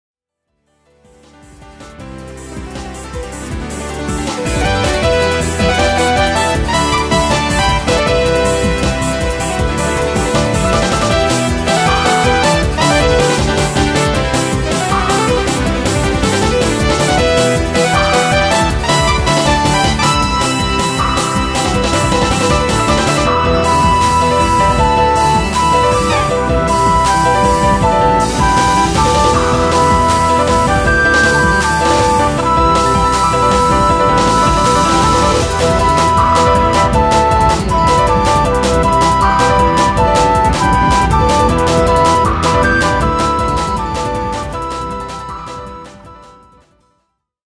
東方風自作曲
楽しい雰囲気が伝わればいいなぁ…。